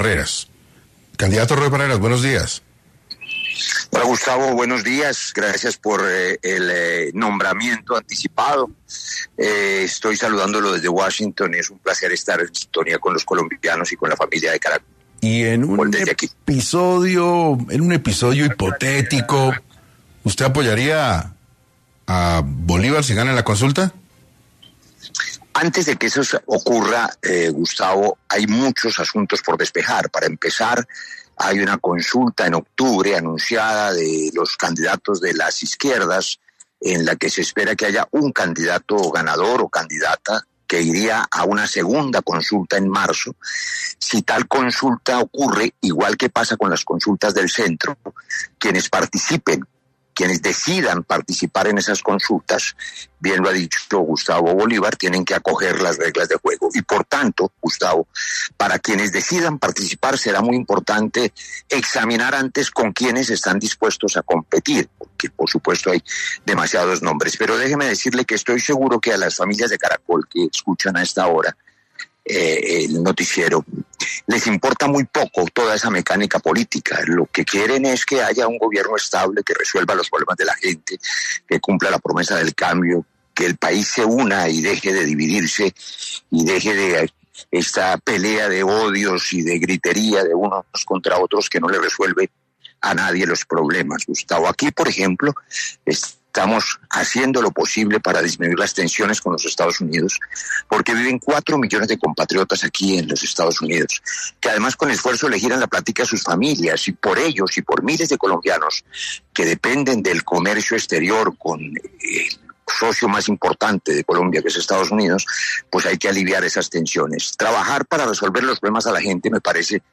En diálogo con 6AM de Caracol Radio, Barreras aclaró que no competirá en la consulta del 26 de octubre, destinada a los precandidatos de izquierda, ya que espera un frente amplio en marzo de 2026, donde un candidato de izquierda competirá con figuras liberales y socialdemócratas.